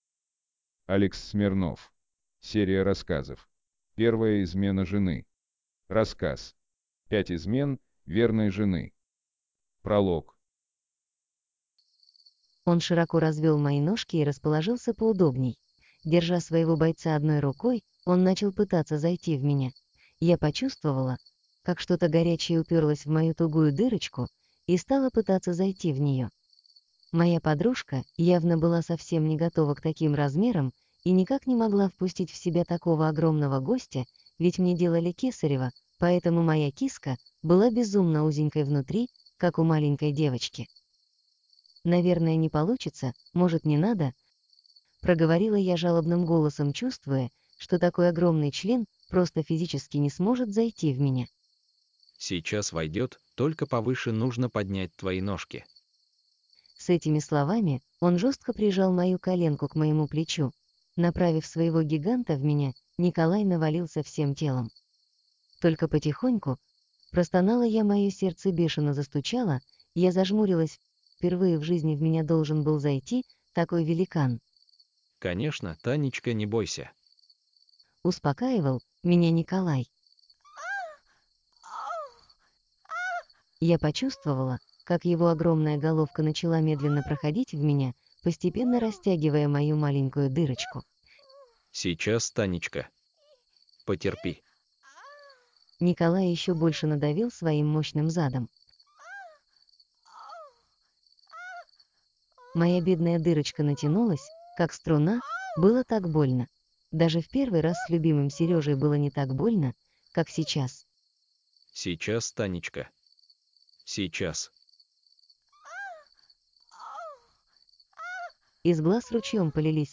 Первая измена жены Автор Алекс Смирнов Читает аудиокнигу Авточтец ЛитРес.